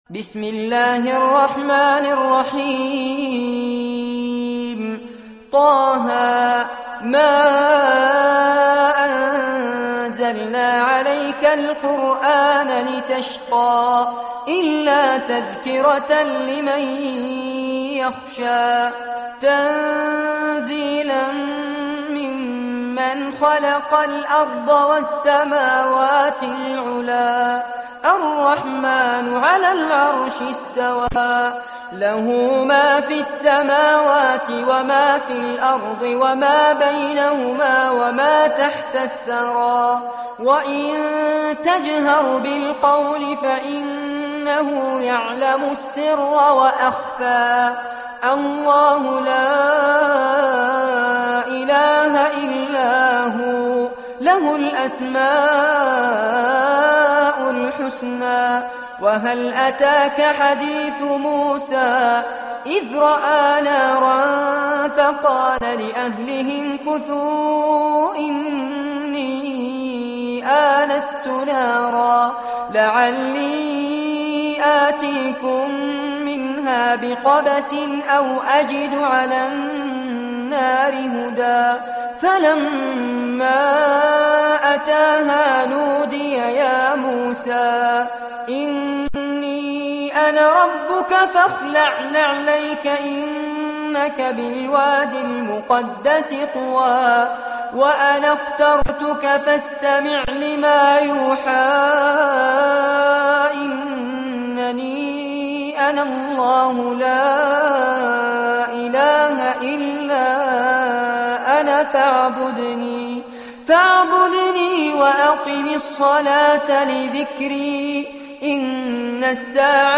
سورة طه- المصحف المرتل كاملاً لفضيلة الشيخ فارس عباد جودة عالية - قسم أغســـــل قلــــبك 2